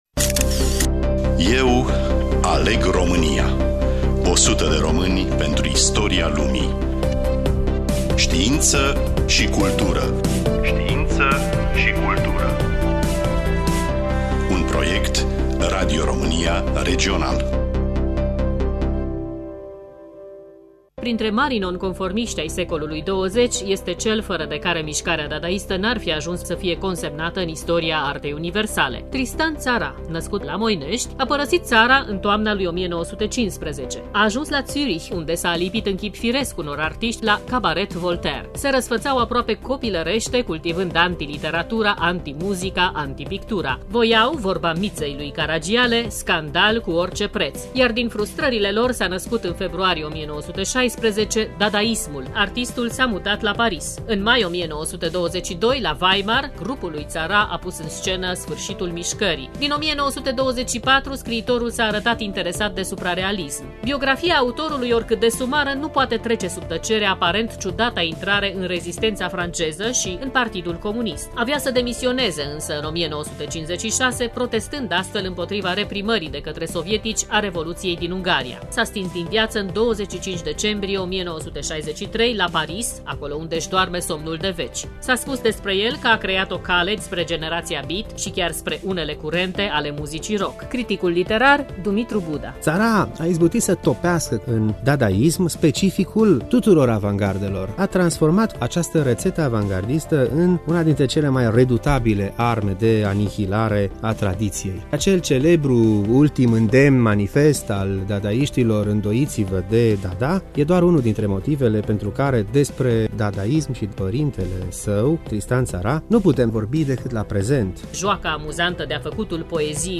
Studioul: Radio România Tg Mures